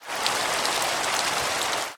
MinecraftConsoles / Minecraft.Client / Windows64Media / Sound / Minecraft / ambient / weather / rain2.ogg
rain2.ogg